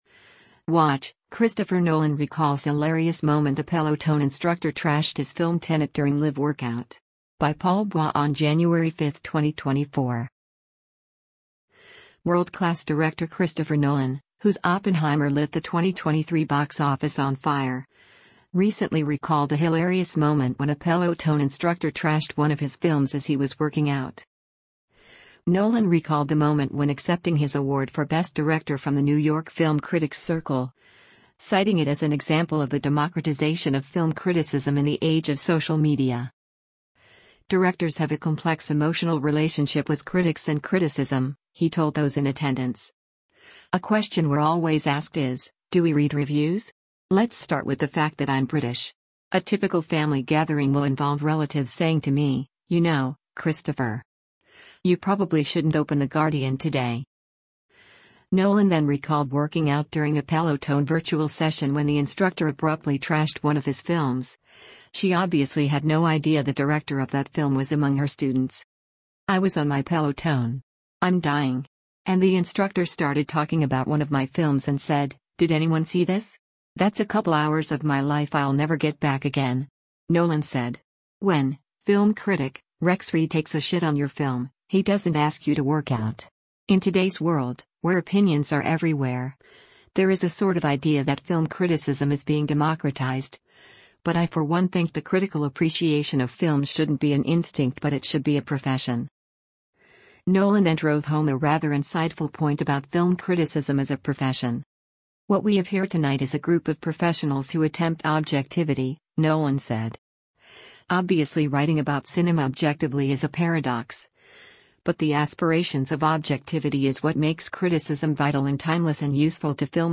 Nolan recalled the moment when accepting his award for Best Director from the New York Film Critics Circle, citing it as an example of the democratization of film criticism in the age of social media.